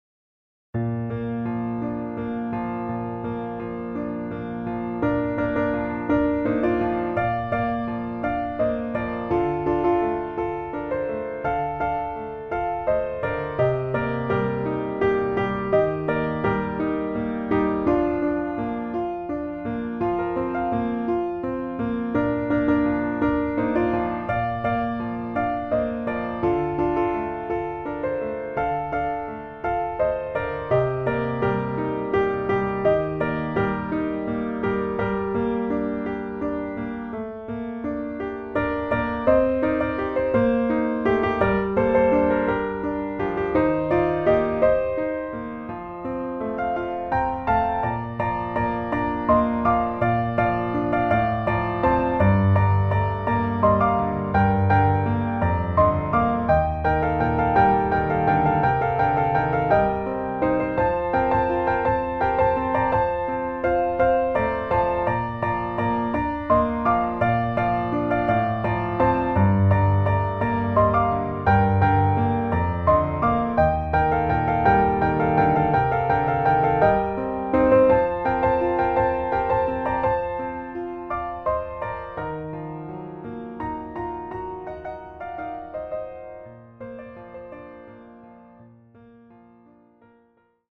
Piano
Tono original: Bb